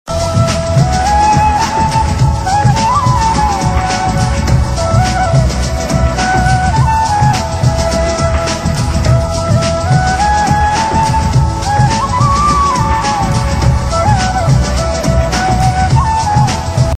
Tv Serial Tones